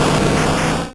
Cri de Tortank dans Pokémon Rouge et Bleu.